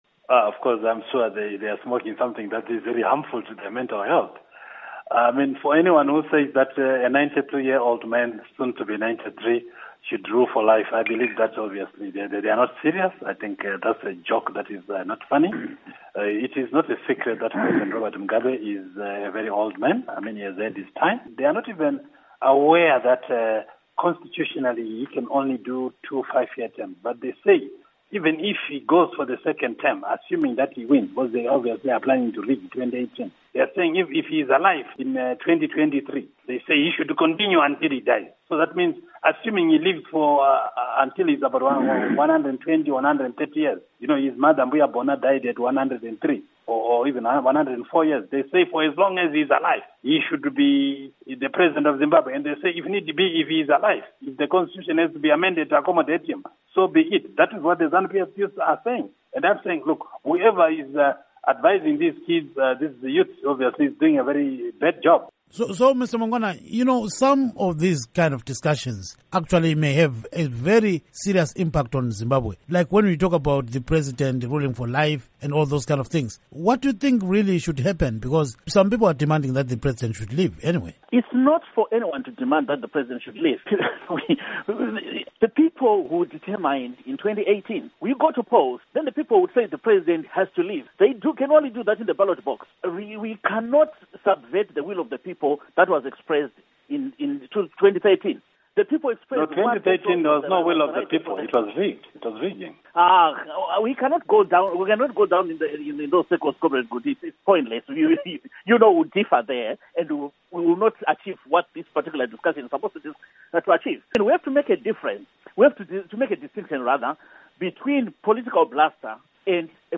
Interview With Obert Gutu And Nick Mangwana on Mugabe Life Presidency